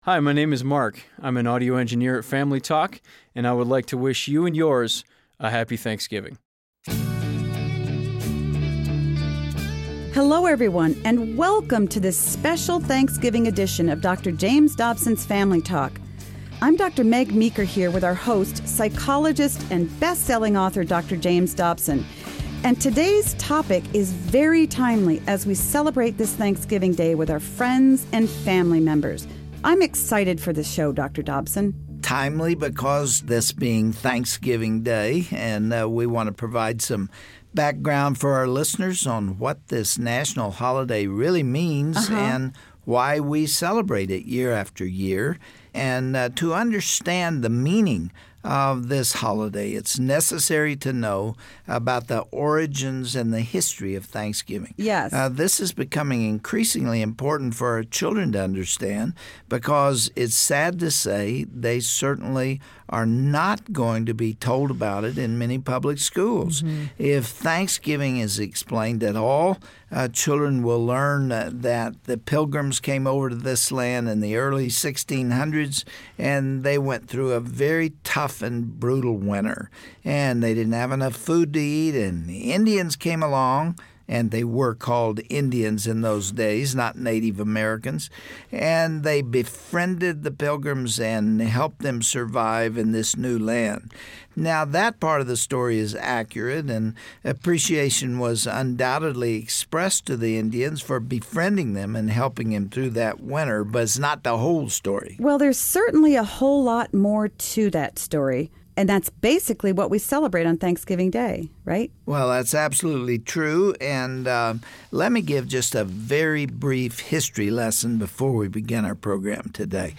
On this broadcast, Dr. Dobson talks with guest Todd Akin about the real reasons behind the Thanksgiving season--beyond what is taught in our schools today.